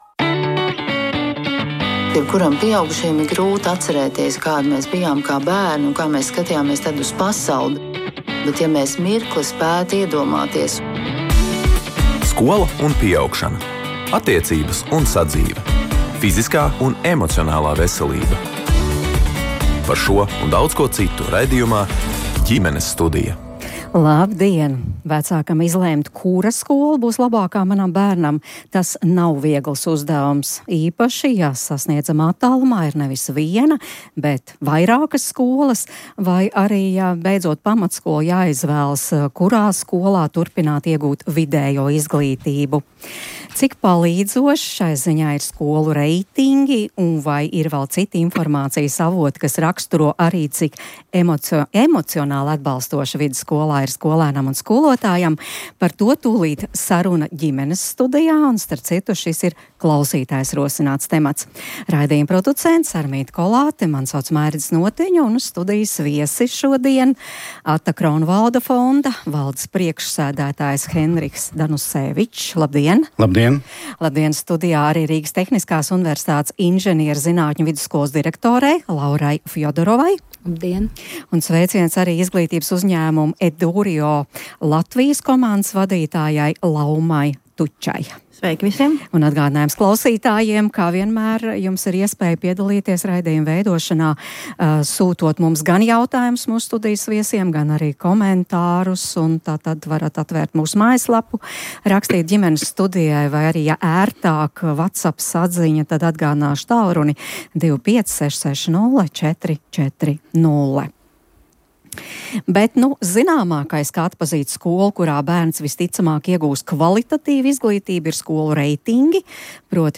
Ierakstā viedojamies arī starpbrīdī Rīgas Katoļu ģimnāzijā.